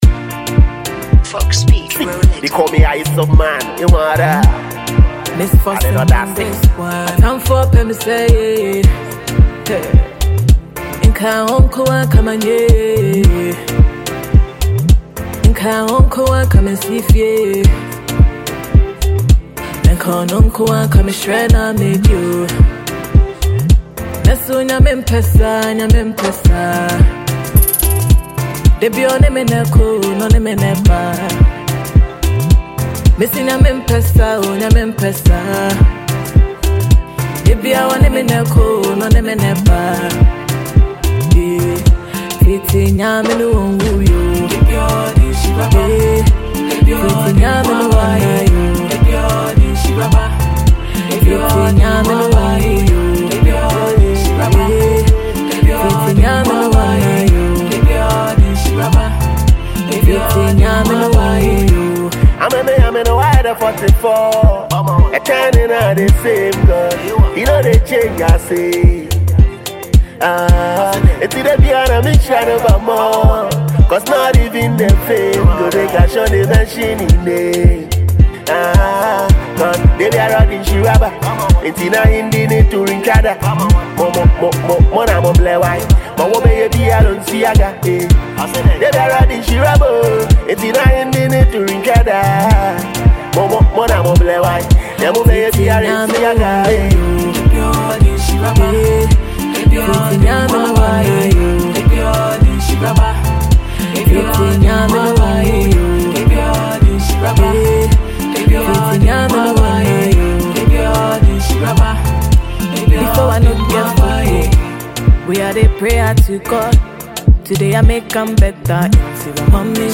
Ghana Music Music